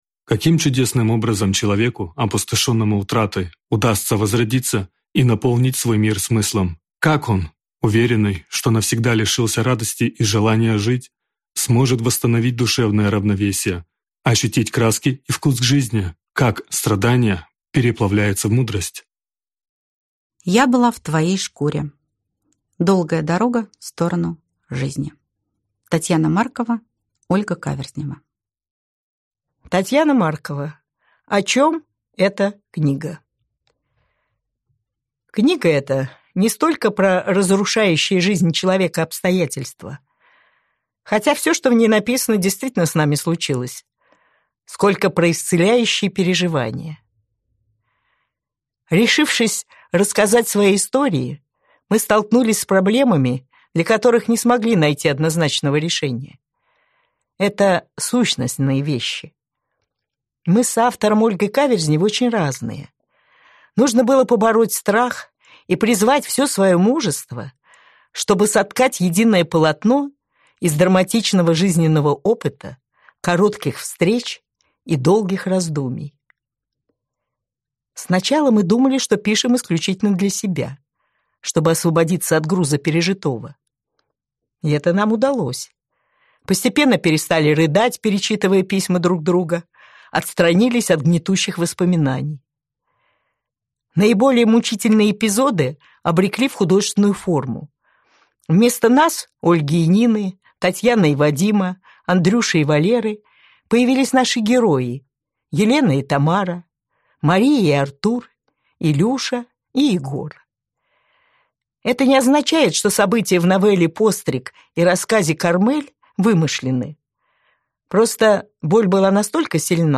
Аудиокнига Я была в твоей шкуре | Библиотека аудиокниг